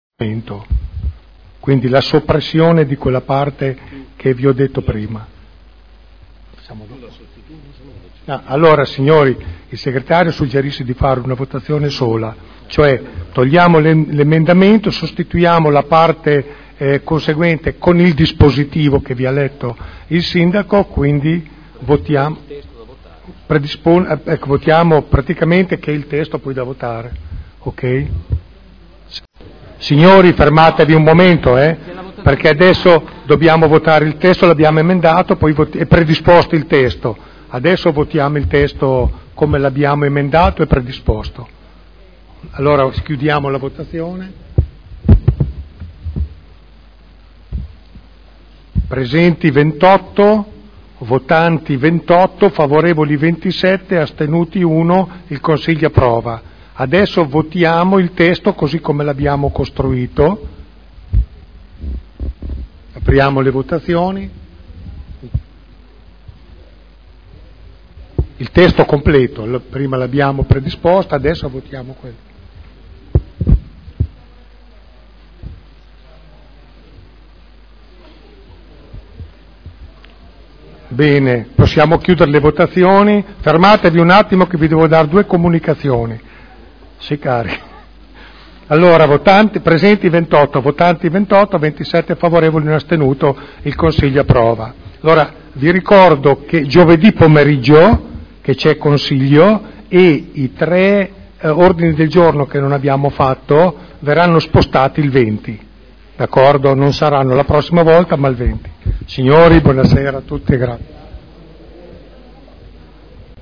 Seduta del 06/06/2011. Mozione presentata dai consiglieri Barcaiuolo, Taddei, Galli, Pellacani, Morandi, Bellei, Vecchi, Santoro (PdL) avente per oggetto: "Trasparenza HERA" .